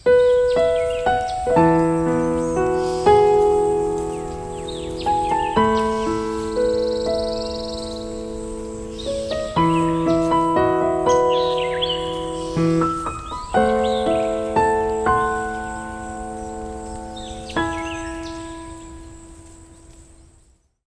weather_alarm_sun2.ogg